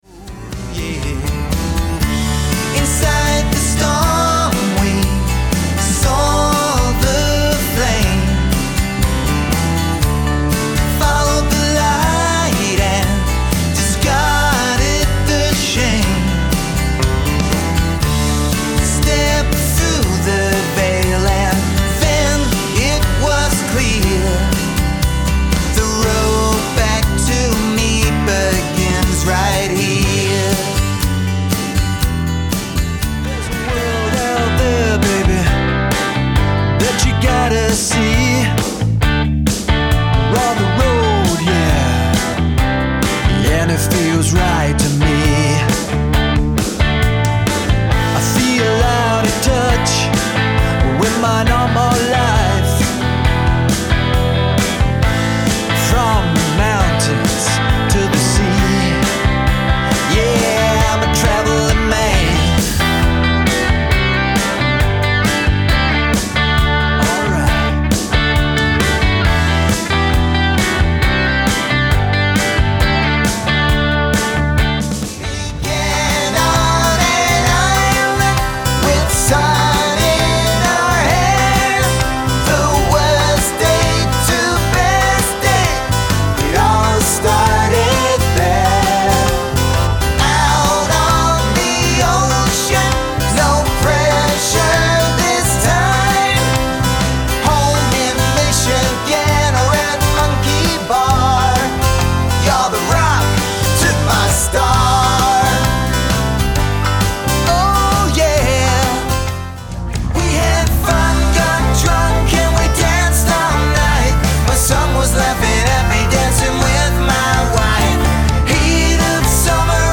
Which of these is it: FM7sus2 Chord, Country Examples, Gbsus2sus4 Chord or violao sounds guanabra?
Country Examples